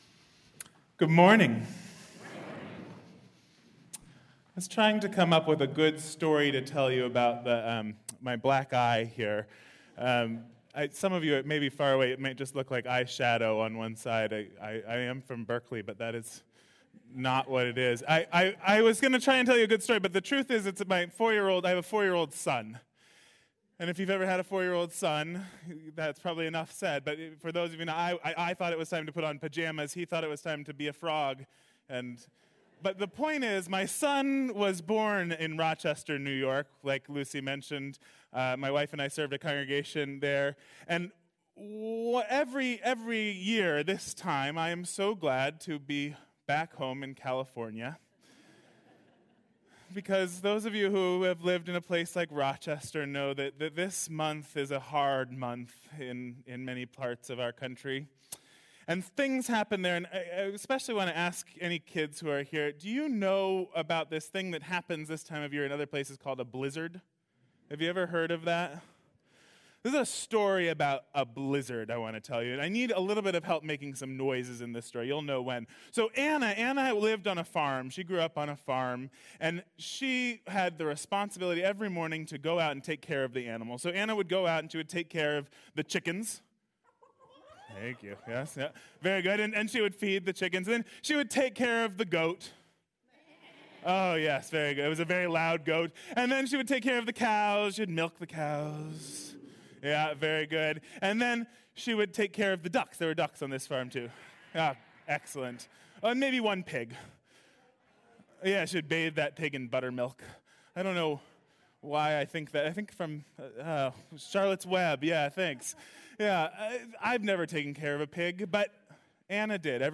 Audio timing notes: 0:00 Introduction 3:36 Reflection 9:25 Sermon Download MP3